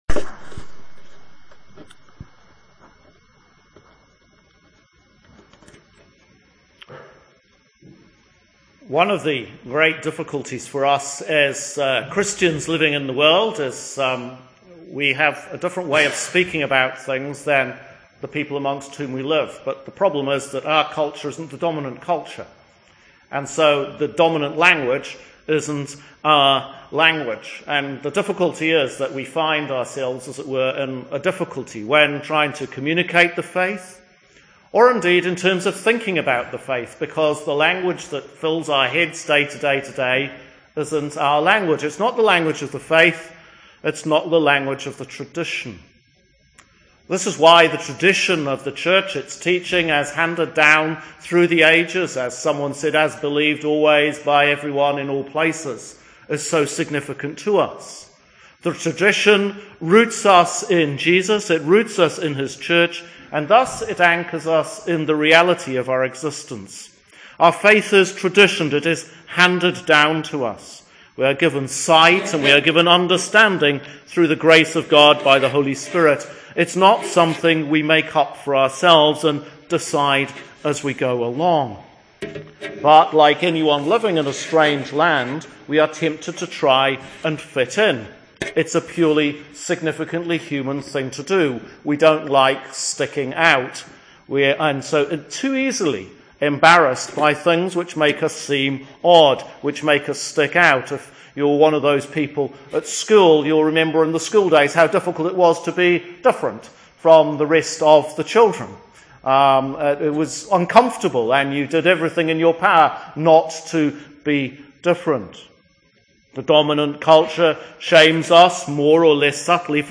‘Male and Female he created them’ Sermons for Advent 4
2 Sermons for the Fourth Sunday of Advent – Ss Joseph and Mary